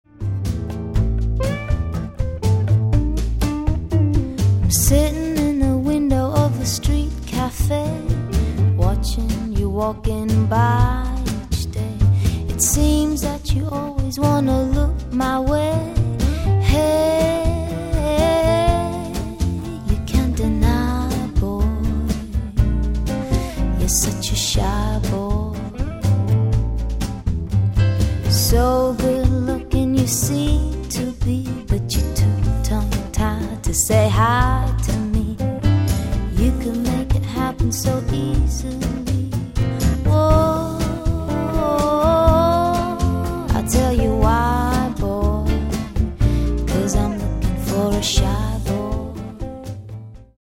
Vocals
Guitars
Piano
Bass
Drums